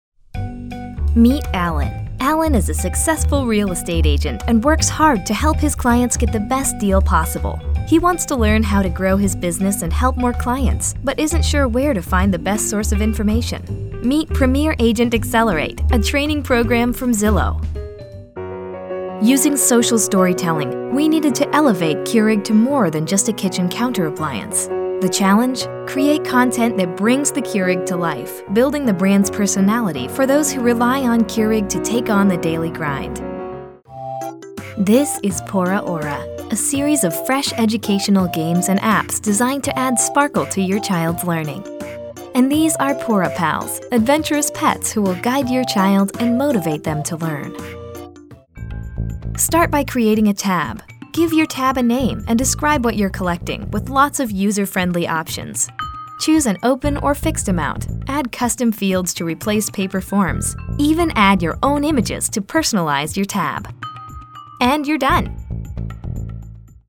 Female Voice Over, Dan Wachs Talent Agency.
Current, Modern, Young Mom, Heartfelt.
Corporate